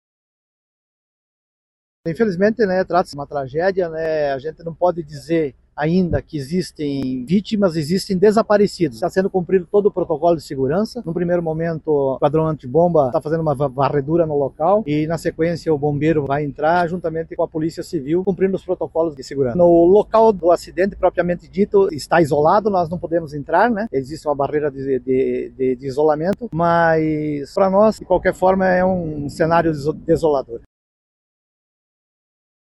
O prefeito de Quatro Barras, Loreno Tolardo (PSD), disse que não pode estar no local da explosão por questão de segurança, mas que o cenário é desolador e que a situação é uma tragédia.